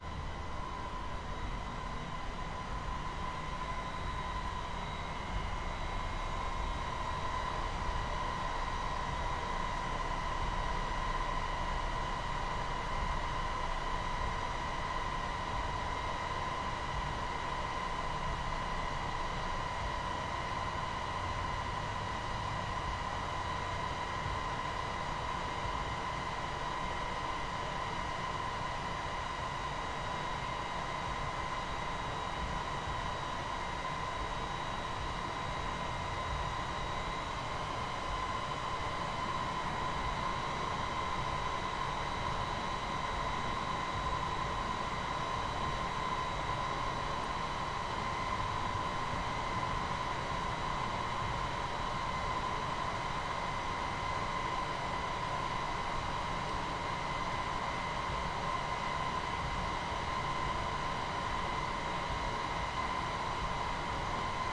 描述：在圣地亚哥拉霍亚剧院大楼内的通风设备
Tag: 静态的 噪声 通风